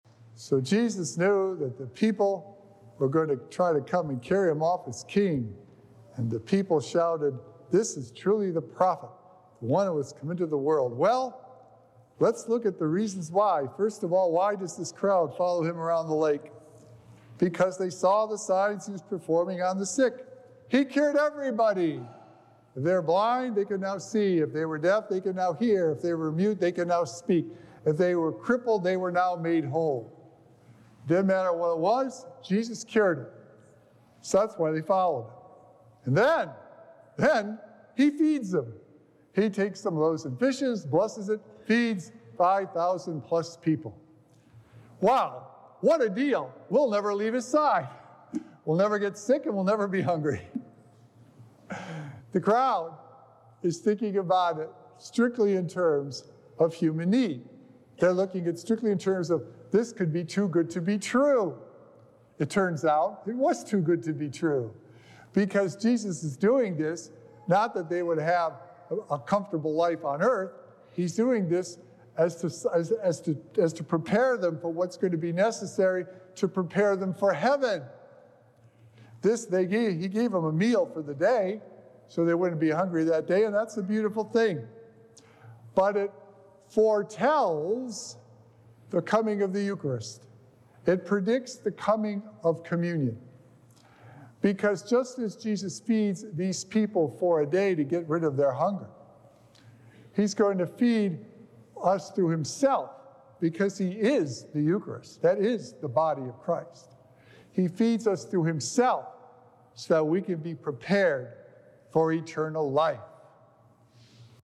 Sacred Echoes - Weekly Homilies Revealed
Just as Jesus fed His people for a day to rid them of their hunger, we can depend on Him to feed us eternal life if we turn to Him. Recorded Live on Sunday, July 28th, 2024 at St. Malachy Catholic Church.